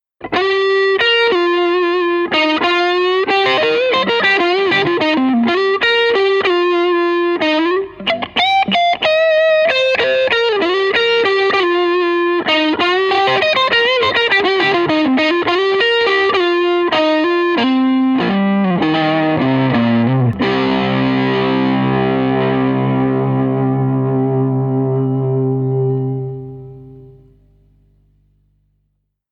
Ici mise en valeur avec la belle saturation du Delta King de chez Supro .
Micro Grave